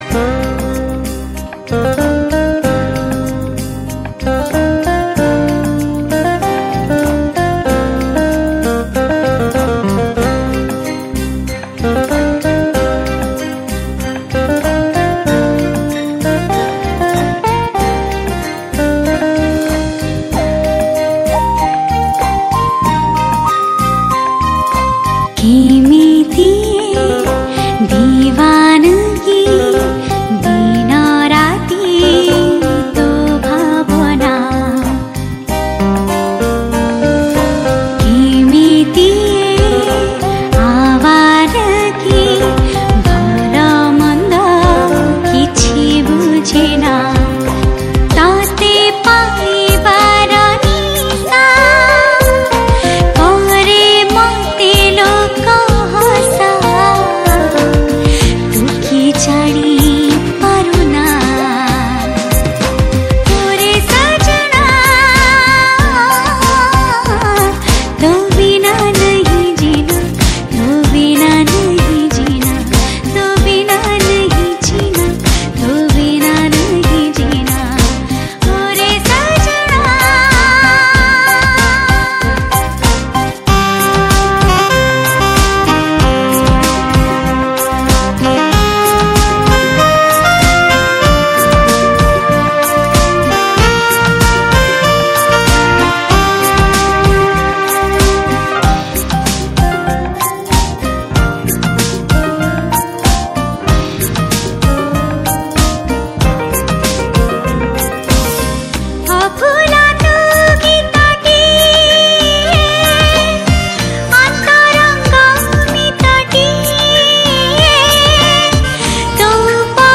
Odia New Romantic Album